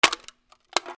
空き缶を潰す 衝撃
『クシャ』